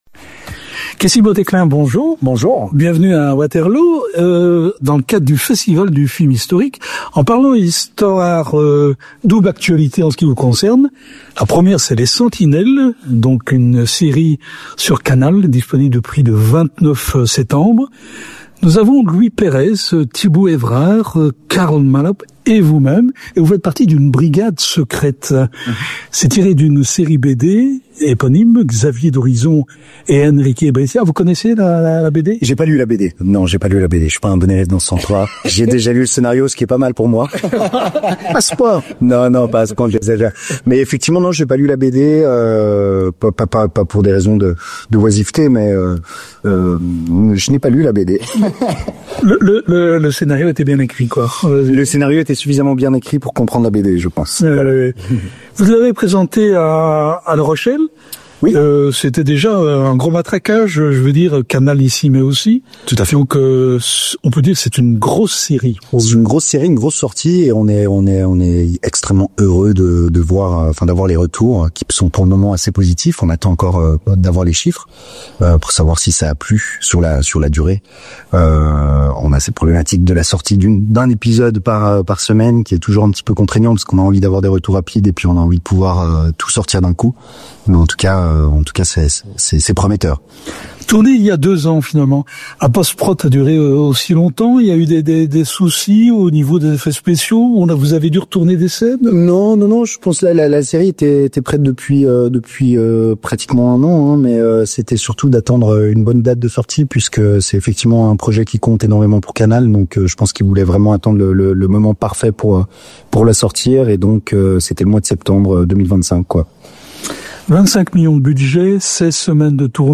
Le comédien suisse est actuellement à Waterloo pour rejoindre le jury du Festival du Film Historique présidé par Régis Wargnier. Rencontre.